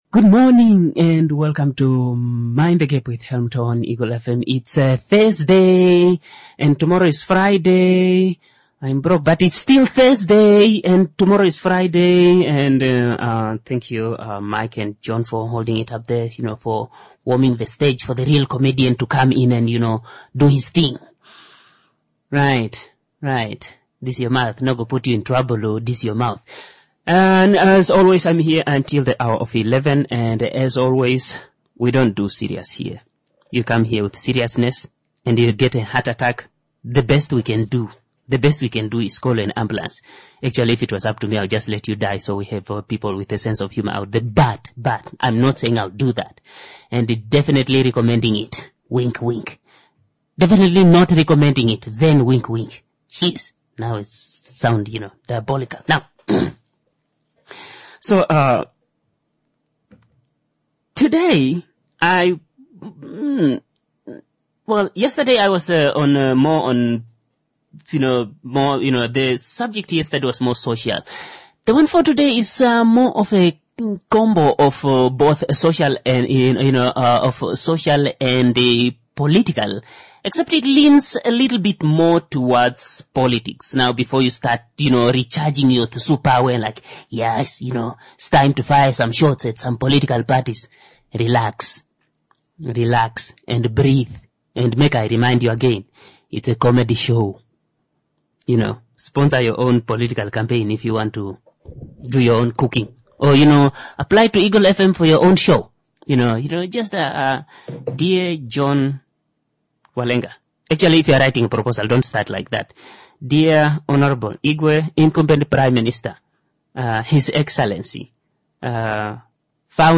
⚠ This is a COMEDY show.